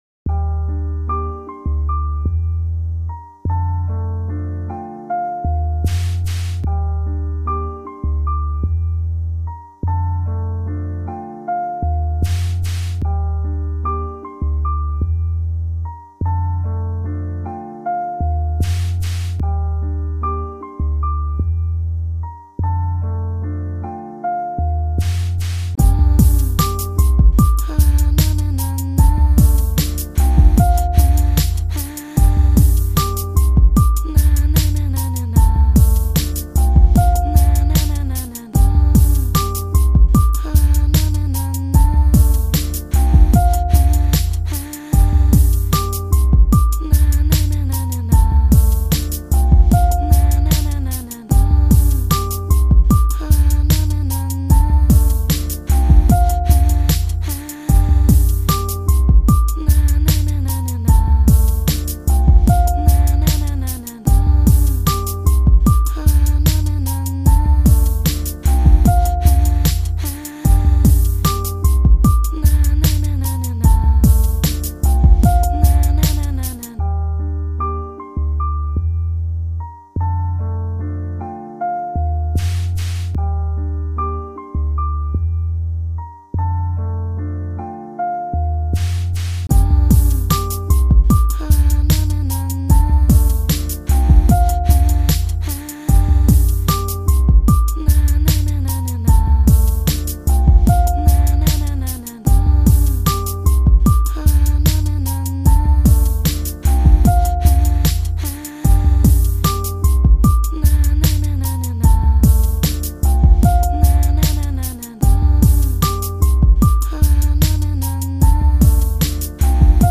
Melankolik Beatler
melankolik_beat.mp3